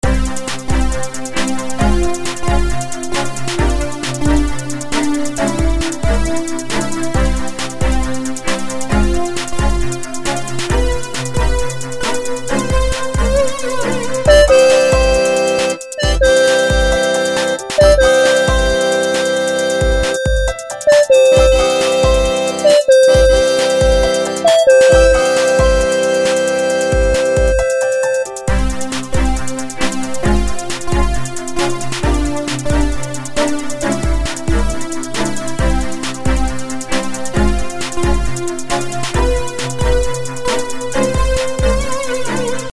作曲と言っても主旋律ほかちょびっとだけで、歌詞に至っては単語のみ繰り返し…（無謀にもテクノっぽさを追求とか豪語してみる。
演奏 G4 with MIDIキーボード